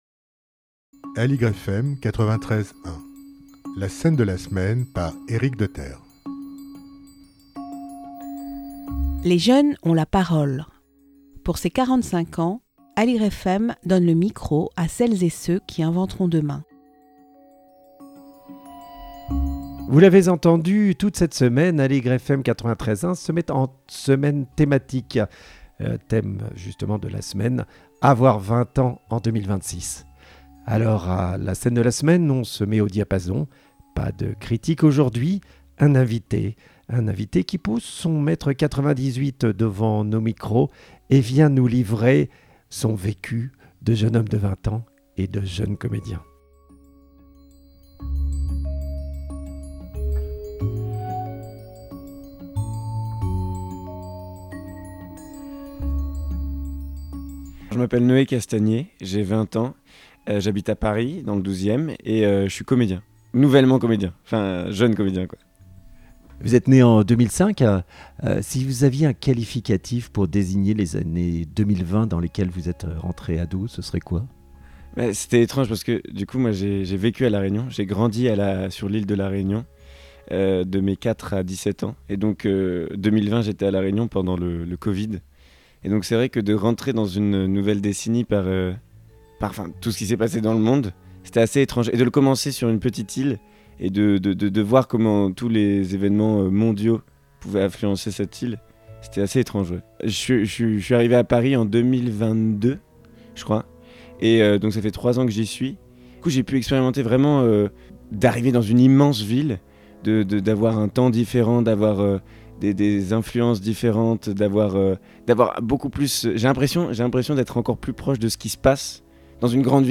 Pas de critique d'un spectacle mais une rencontre avec un jeune comédien de 20 ans.